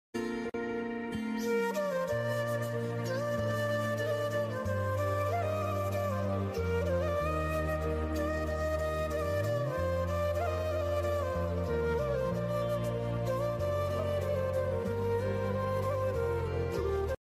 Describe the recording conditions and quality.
Location: 📍Shiv Sagar Mandir, Post de Flacq.